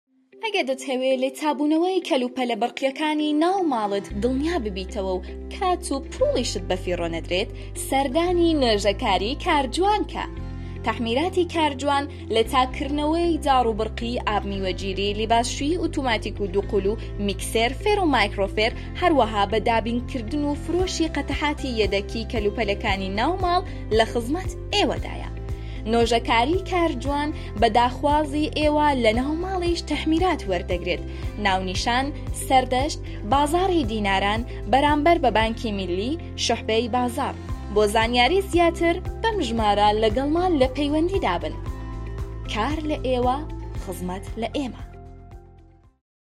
Female
Young
Commercial